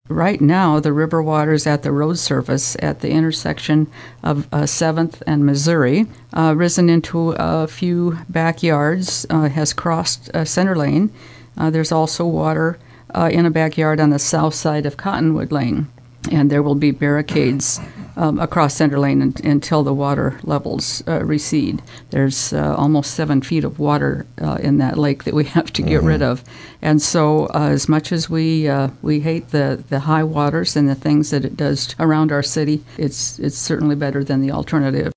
Fort Pierre mayor Gloria Hanson says water coming out at that rate affects some areas of town.